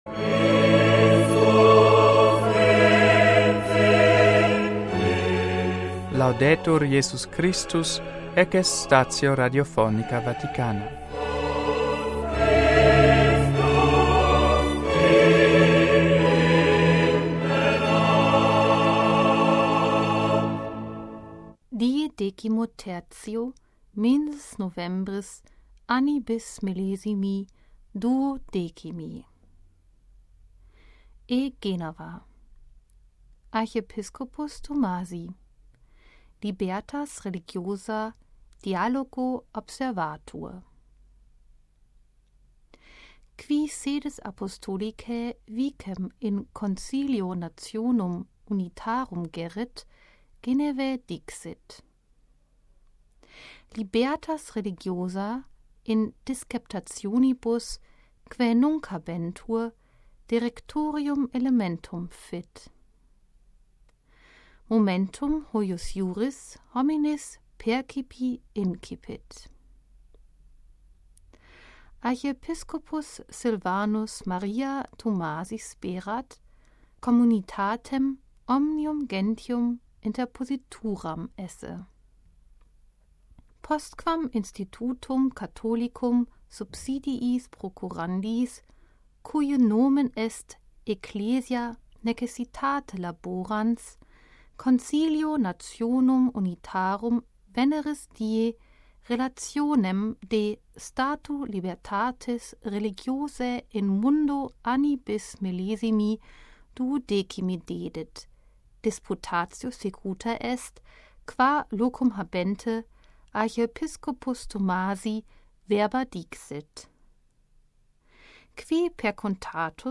NUNTII STATIONIS RADIOPHONICAE VATICANAE PARTITIONIS GERMANICAE IN LINGUAM LATINAM VERSI